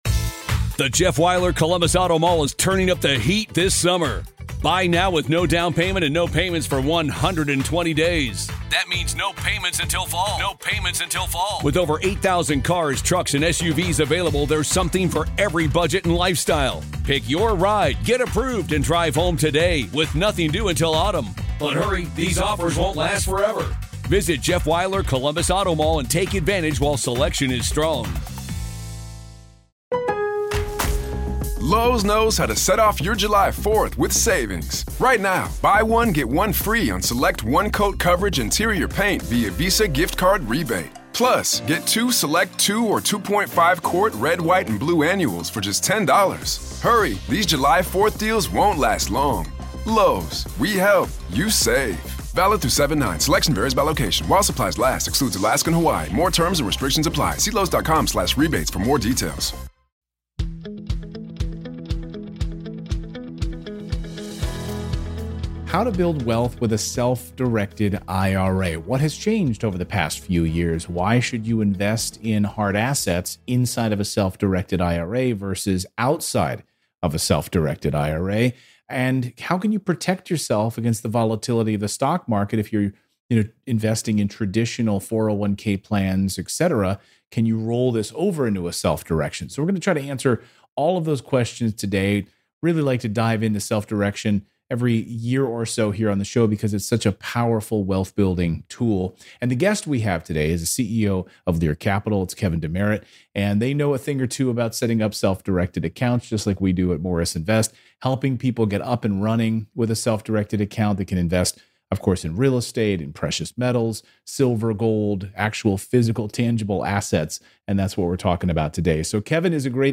In this interview, he's sharing the ins and outs of the self-directed IRA.